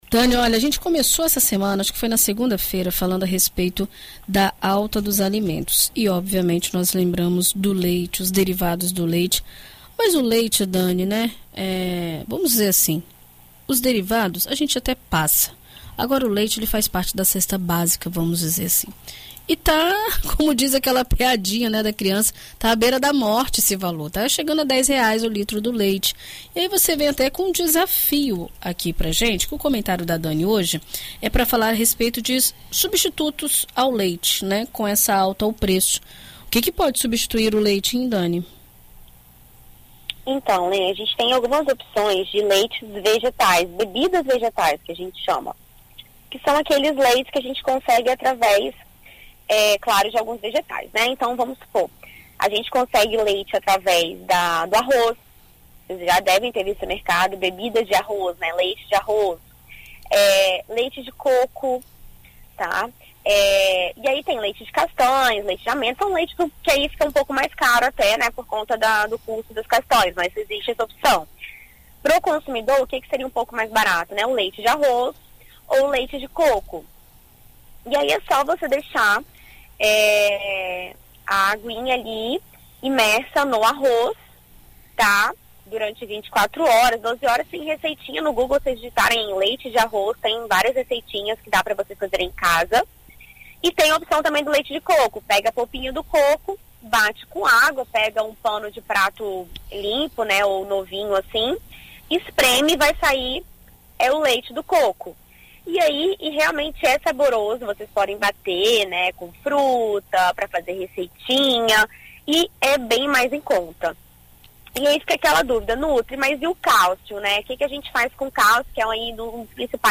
A alta no preço do leite tem deixado muitas pessoas com poucas alternativas quando se trata do consumo diário do produto, considerado essencial na mesa dos brasileiros. Na coluna Viver Bem desta quarta-feira (17), na BandNews FM Espírito Santo,